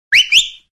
whistle5.ogg